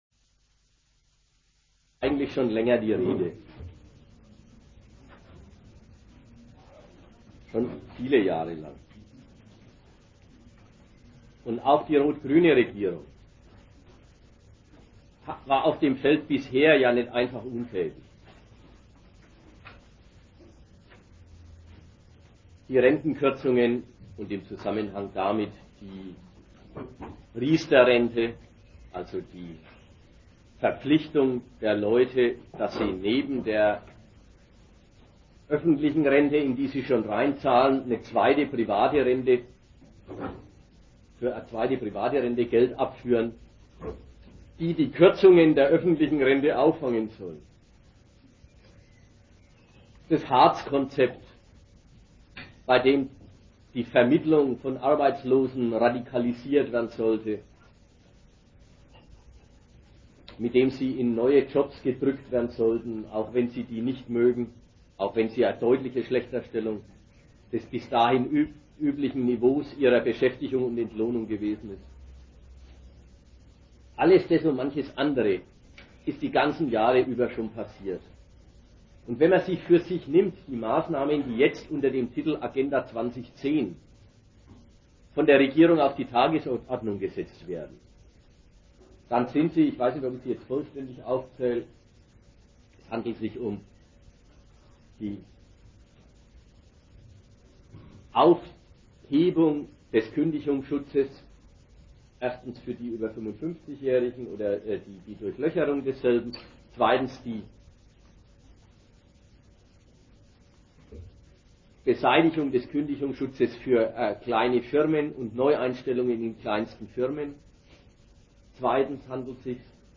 Der Kanzler sagt es gerade heraus: Der Kapitalismus kann nur wieder blühen, wenn niemand versucht, die zu ihm gehörige Armut zu korrigieren. Veranstalter: Sozialistische Gruppe